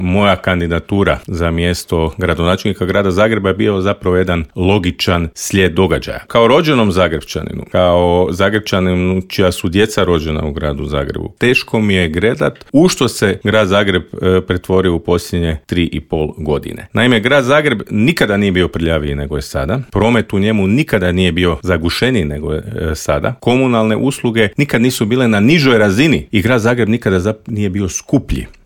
Kandidat HDZ-a za čelno mjesto metropole je Mislav Herman koji je u Intervjuu Media servisa poručio da stanje u gradu nikada nije bilo gore: